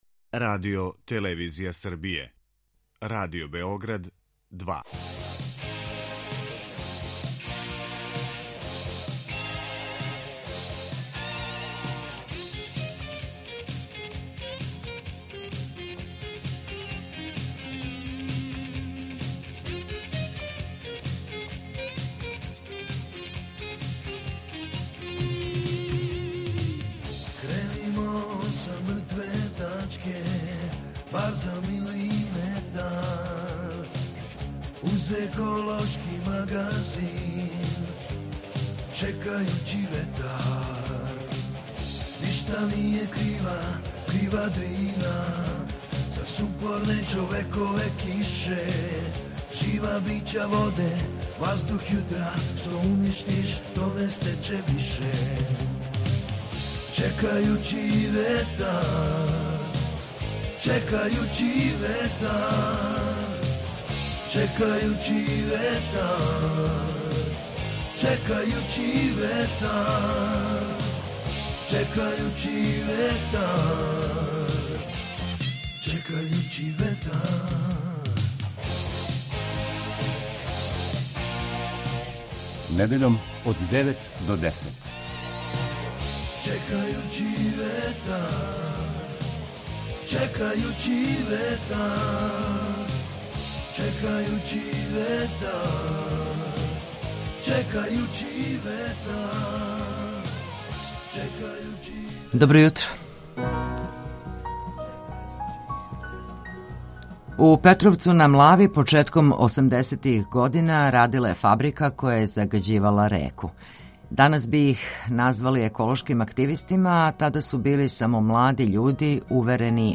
ekološki magazin Radio Beograda 2 koji se bavi odnosom čoveka i životne sredine, čoveka i prirode.